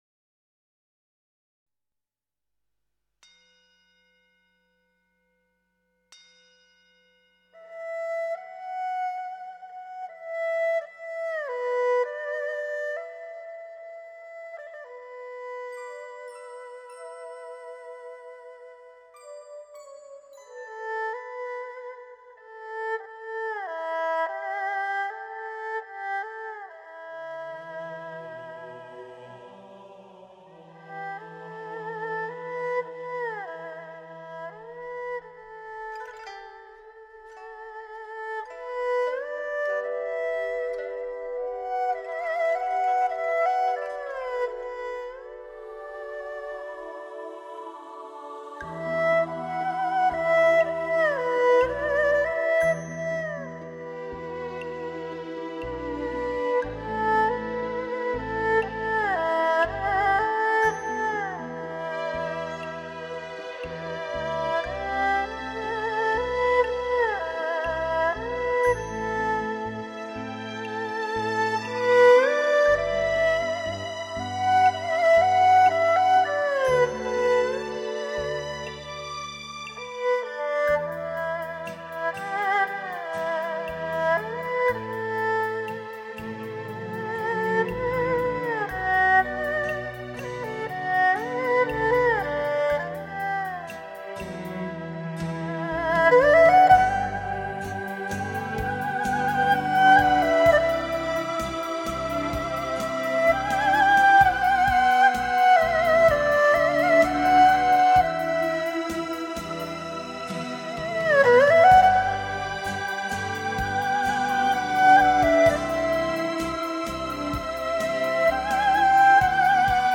中国的拉弦乐器之代表——二胡，以可歌、可诉、可愤、可吟的音色，演奏了许多优秀名曲。